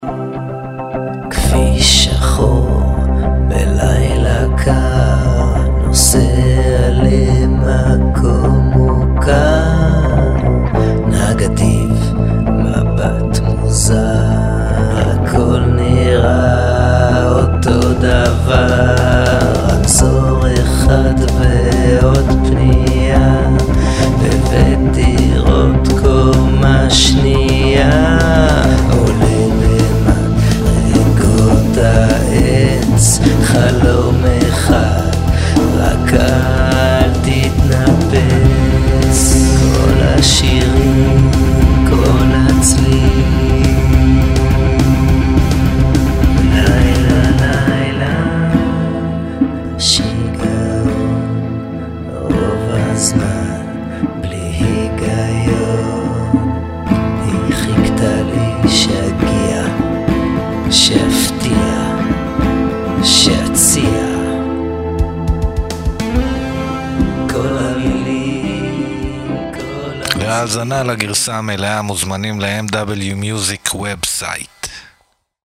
זמר־יוצר עם קול ייחודי ונוגע
כולם עטופים בהפקה מוקפדת ועדינה.